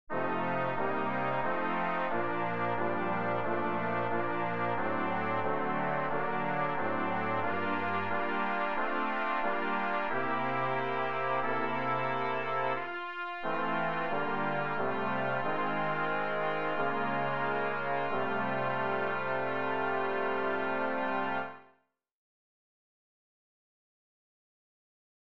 Key written in: F Major
How many parts: 4
Type: Barbershop